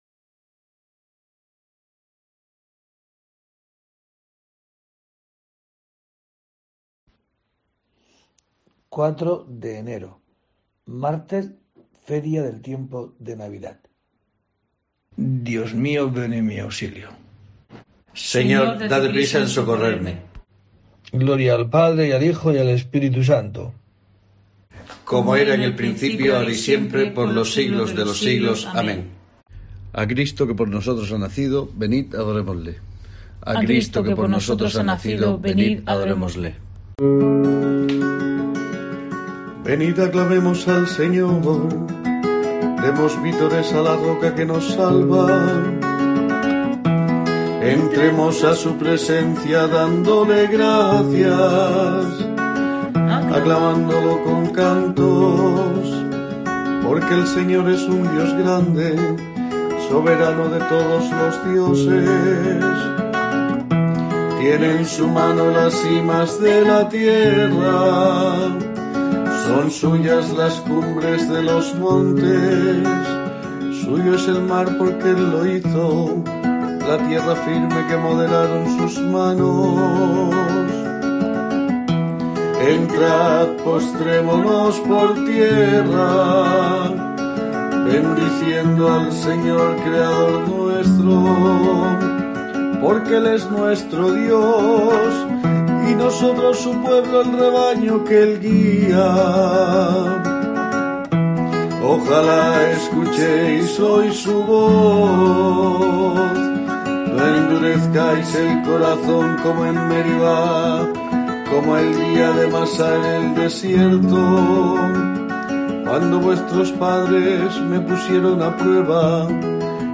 04 de enero: COPE te trae el rezo diario de los Laudes para acompañarte